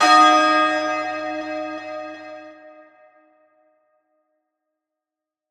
Key-bell_191.1.1.wav